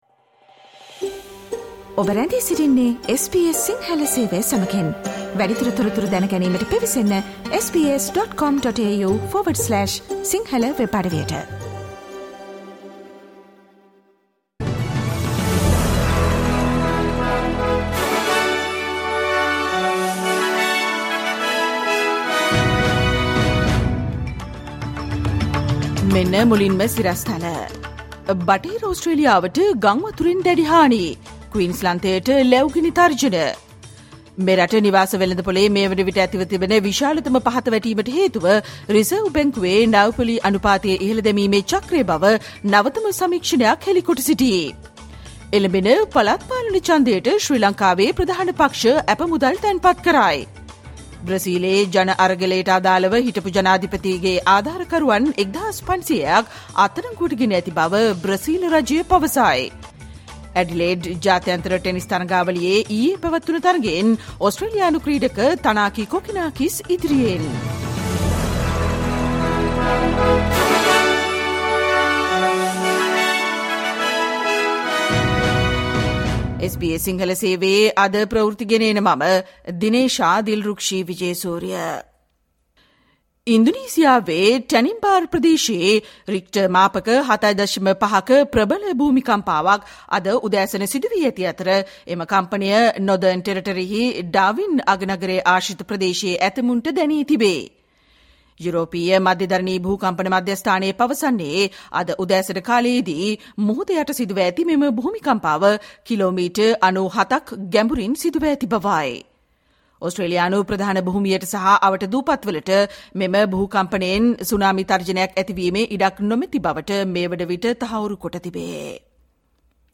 Listen to the SBS Sinhala Radio news bulletin on Tuesday 10 January 2022